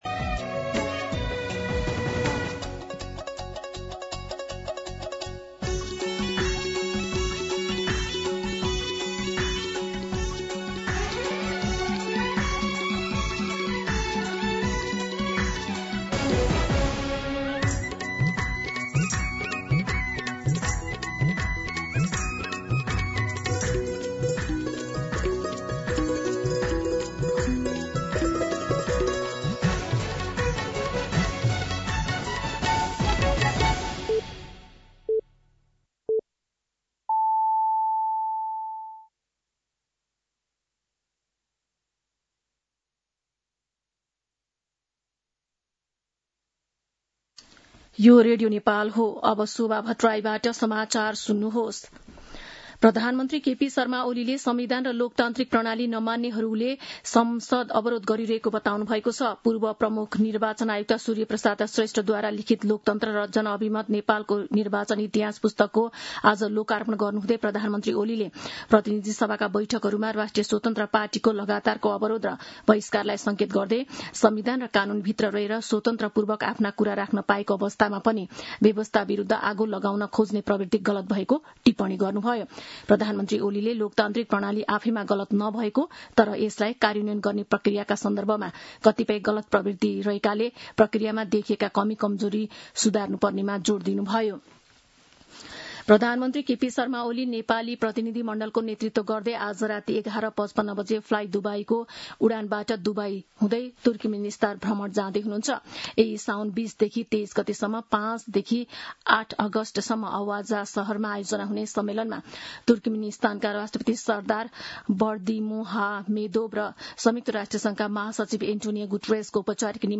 दिउँसो ४ बजेको नेपाली समाचार : १८ साउन , २०८२